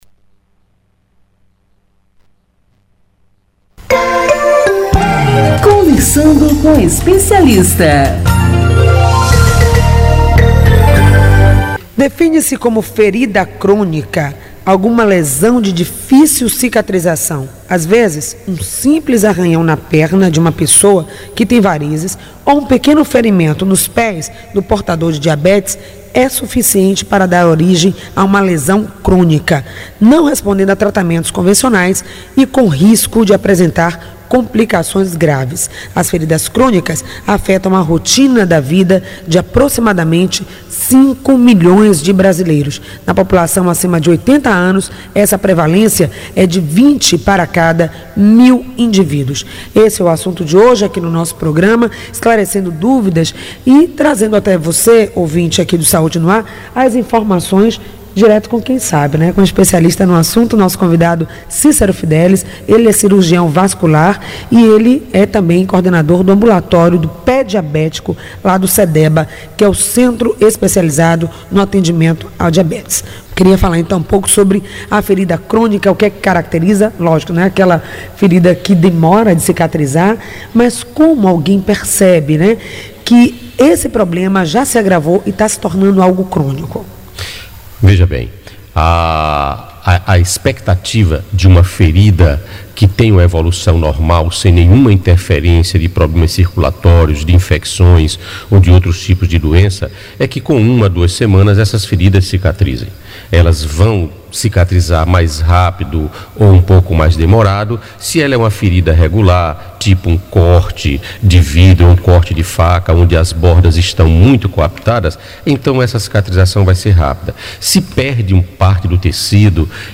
Ouça na íntegra a entrevista!